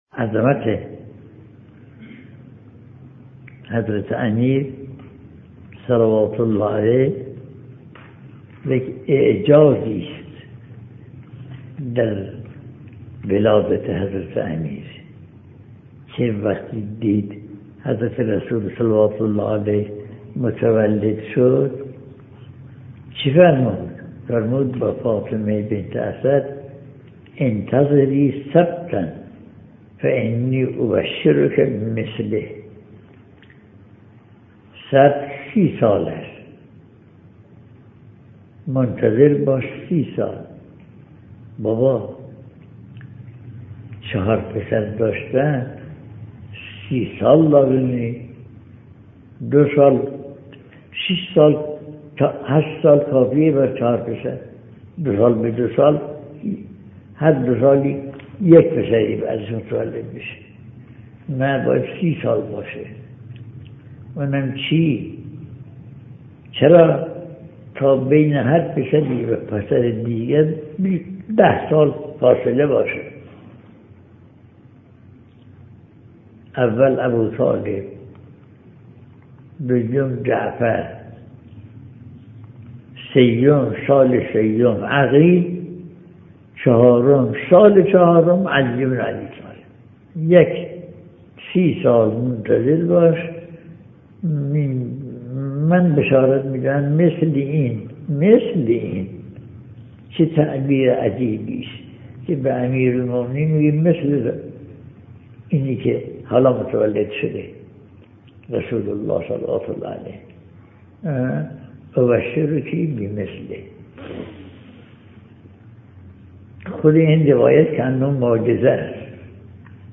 سخنرانی مذهبی